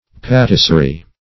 patisserie \pa`tis`serie"\, Patisserie \P[^a]`tis`serie"\, n.